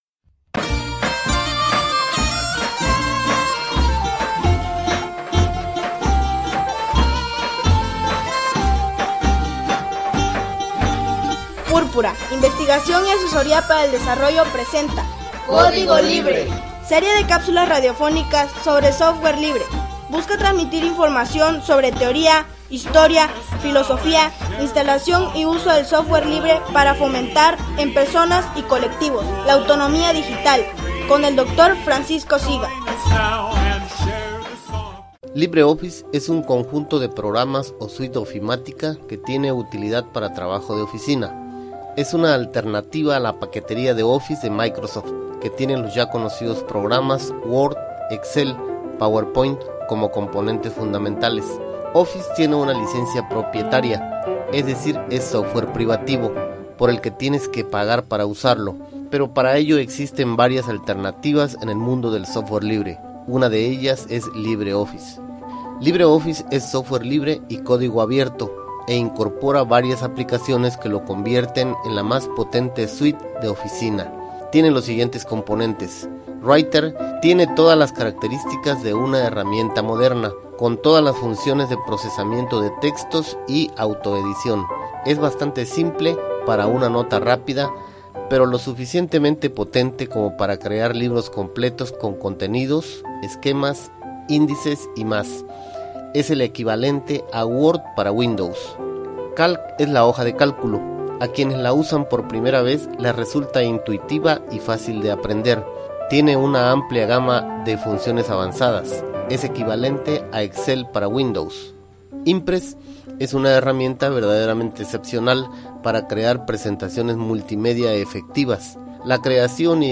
Serie de capsulas radiofónicas sobre Software Libre.